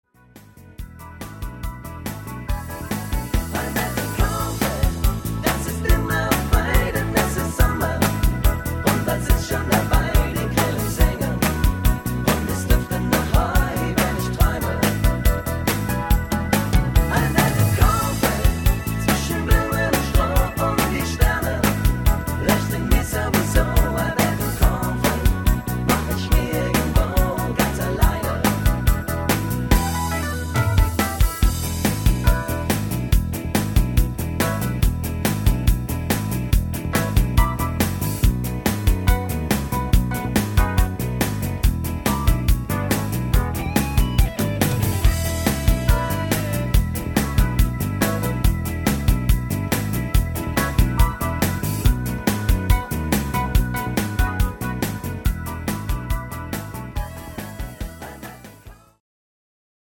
Party Version